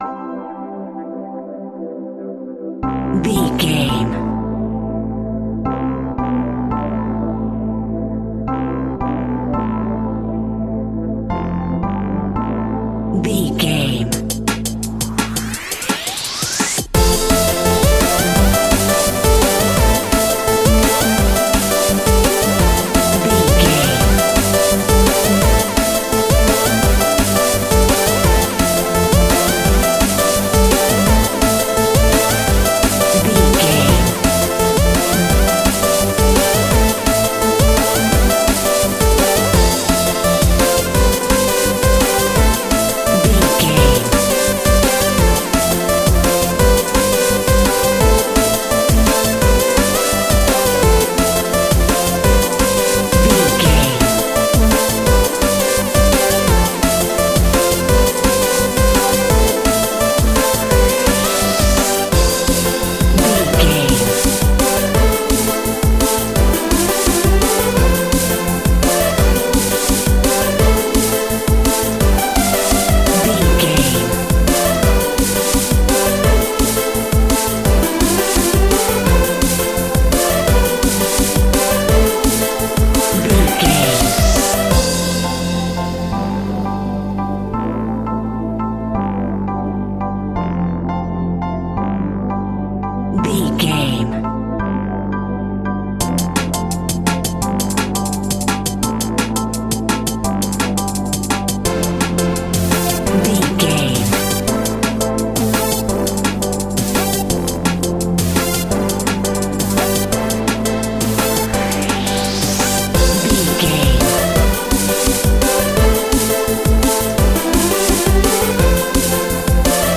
Clubbing Drum and Bass.
Aeolian/Minor
Fast
futuristic
hypnotic
industrial
dreamy
frantic
powerful
drum machine
synthesiser
break beat
electronic
sub bass
synth leads
synth bass